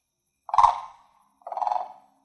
Contact Call | A soft, purring call expressing reassurance and location.
Blue-Contact-Call.mp3